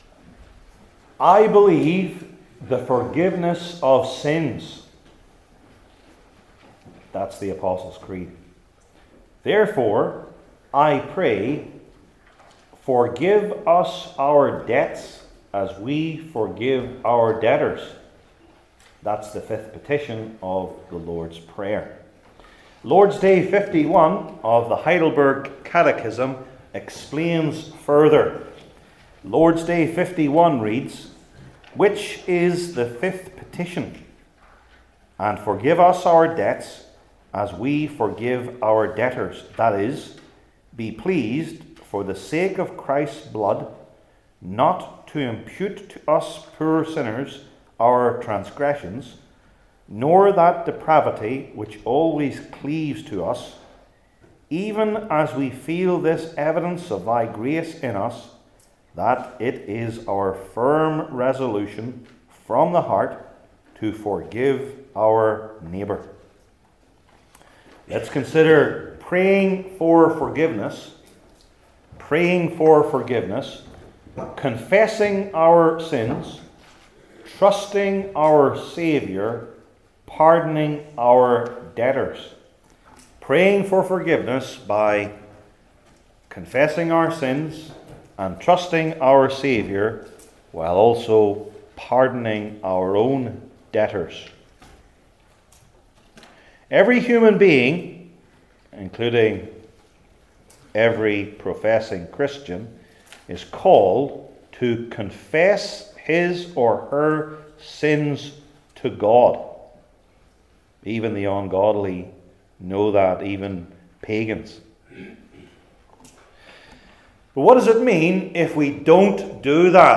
Heidelberg Catechism Sermons I. Confessing Our Sins II.